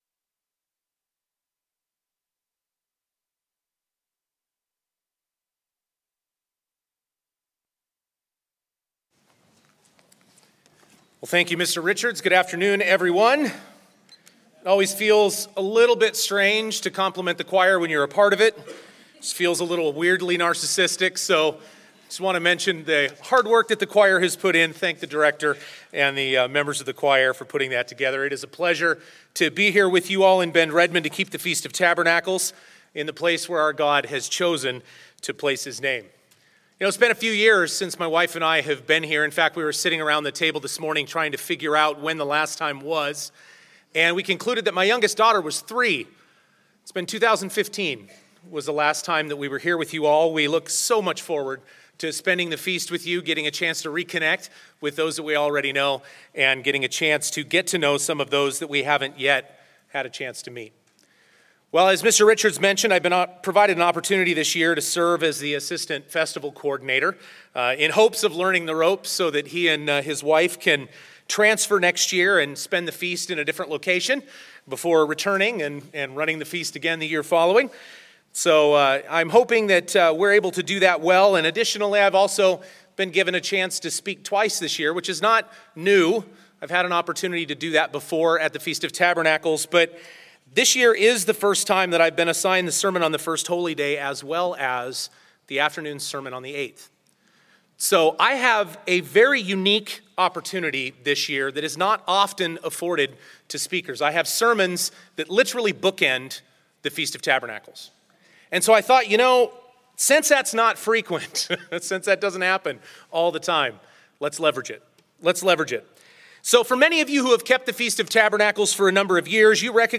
This sermon was given at the Bend-Redmond, Oregon 2019 Feast site.